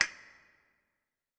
WCASTANET.wav